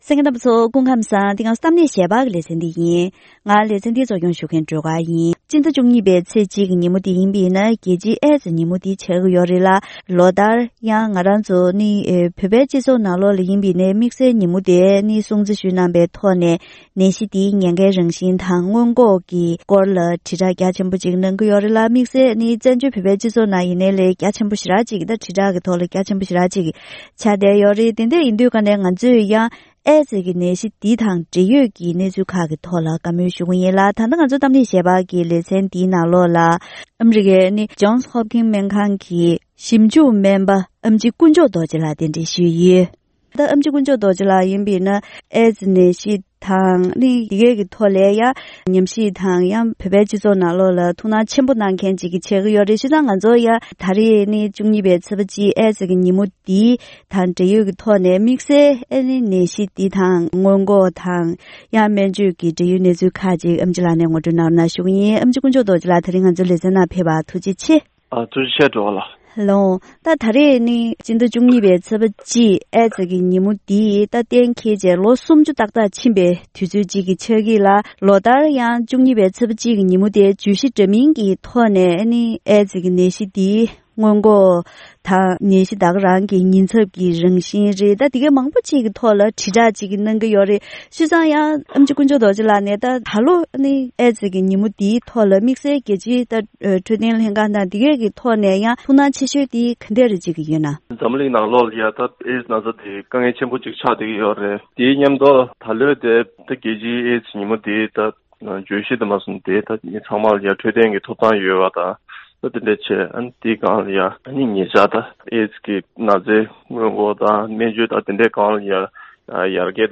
༄༅། །ཐེངས་འདིའི་གཏམ་གླེང་ཞལ་པར་གྱི་ལེ་ཚན་ནང་འཛམ་གླིང་ཨད་ཙེ་ཉིན་མོ་དང་འབྲེལ་ཨད་ཙེ་ནད་གཞི་འདིའི་ཉེན་ཁའི་རང་བཞིན་དང་སྔོན་འགོག་བྱ་ཕྱོགས།